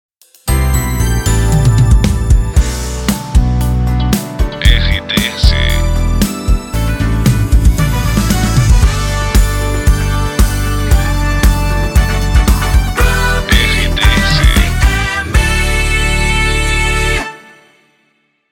Volta de Bloco Longa